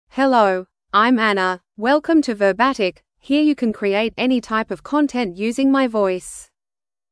Anna — Female English (Australia) AI Voice | TTS, Voice Cloning & Video | Verbatik AI
Anna is a female AI voice for English (Australia).
Voice sample
Female
Anna delivers clear pronunciation with authentic Australia English intonation, making your content sound professionally produced.